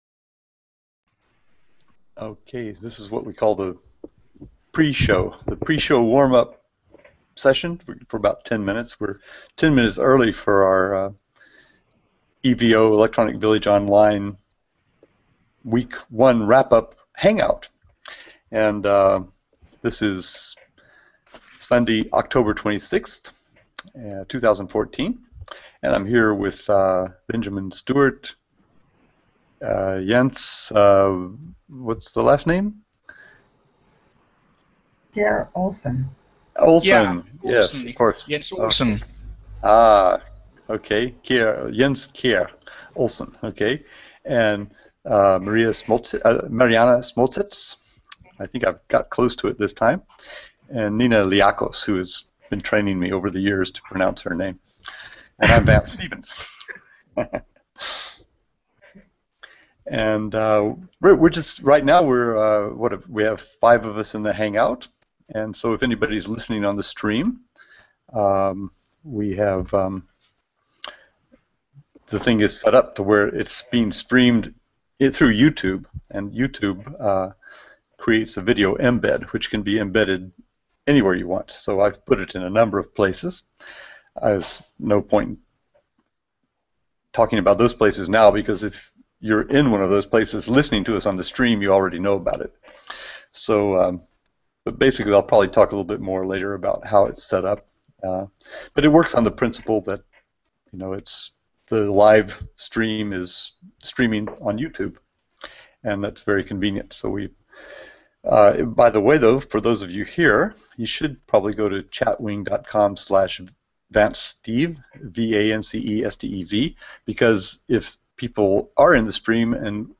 evo-moderator-training-wrap-up-hangout-for-week-1-c8relpdojpm.mp3